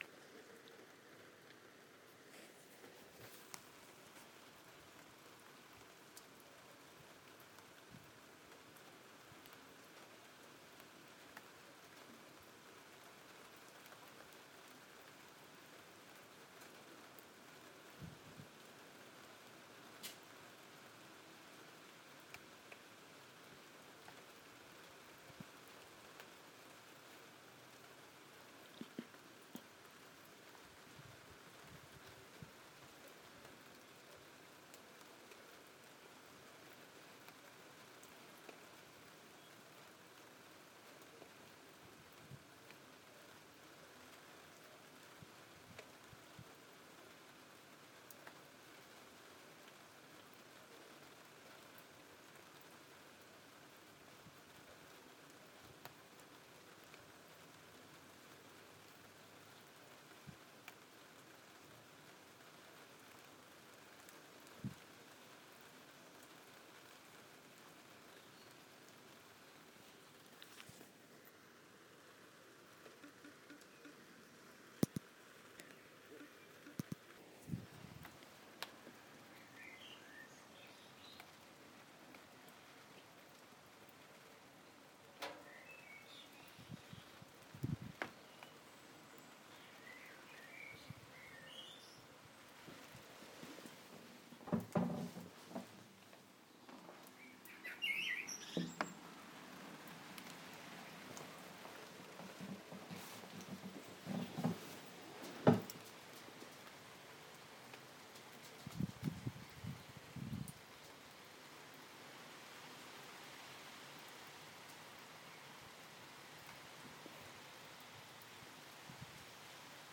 Singing in the rain (a blackbird!)